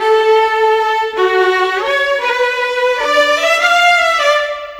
Rock-Pop 10 Violin 02.wav